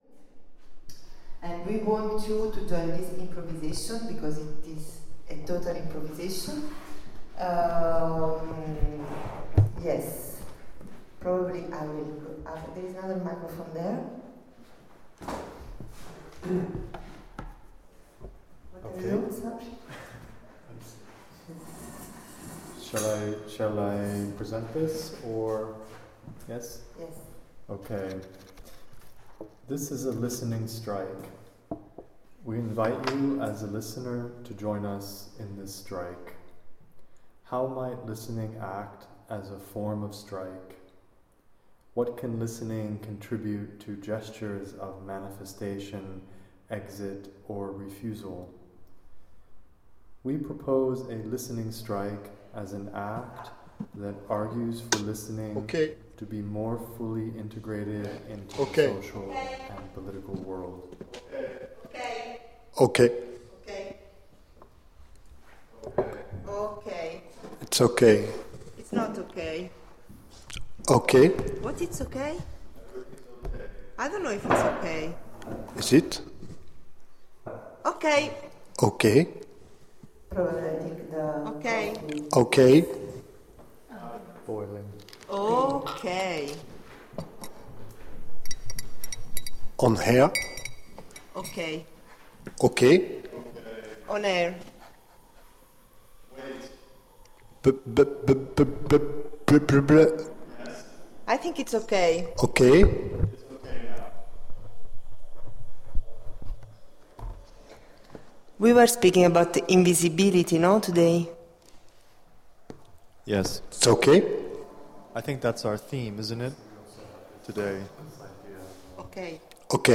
Lab: February 11 - 16, 2019 / with daily radio streaming Venue: Q-02, Brussels